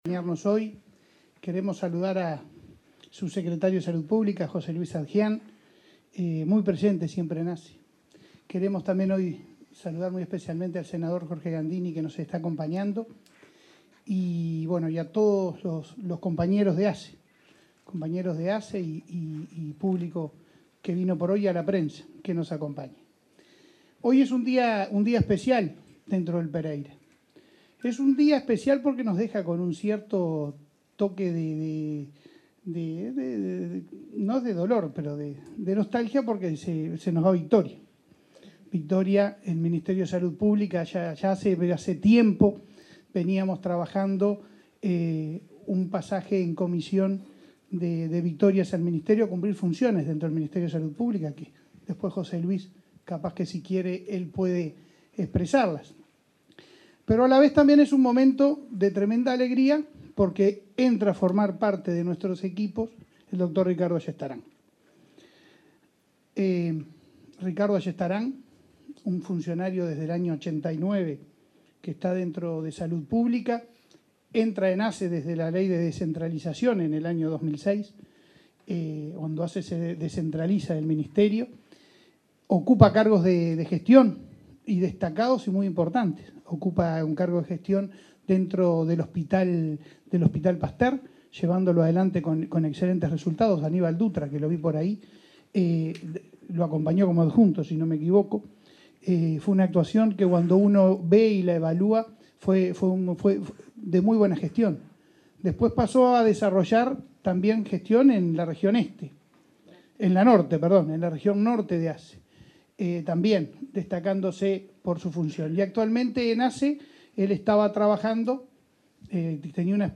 Palabras de autoridades en asunción del director del Pereira Rossell
Palabras de autoridades en asunción del director del Pereira Rossell 29/12/2022 Compartir Facebook X Copiar enlace WhatsApp LinkedIn El miércoles 28, el presidente de la Administración de los Servicios de Salud del Estado (ASSE), Leonardo Cipriani; la directora saliente del hospital Pereira Rossell, Victoria Lafluf, el nuevo director del nosocomio, Ricardo Ayestarán, y el subsecretario de Salud Pública, José Luis Satdjian, disertaron durante el acto de asunción de Ayestarán al frente del hospital pediátrico.